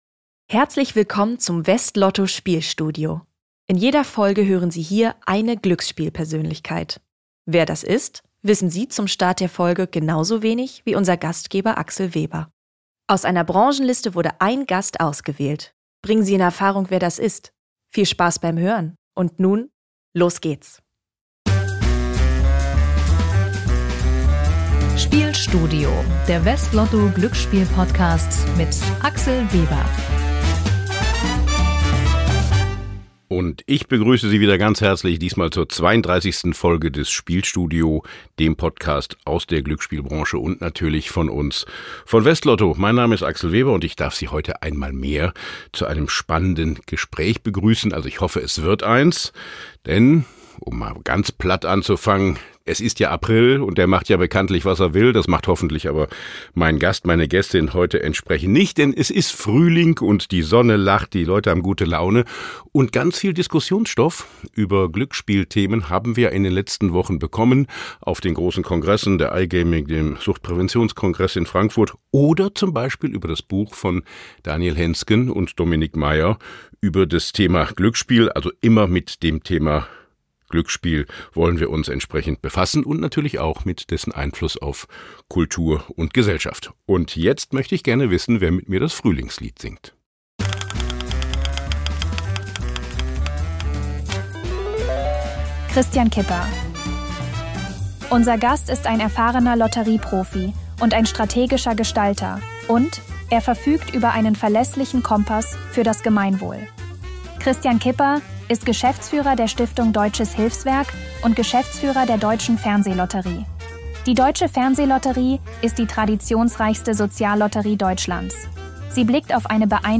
Hinweis: Diese Folge enthält einen Zuspieler, der von einer KI-Stimme gesprochen wird.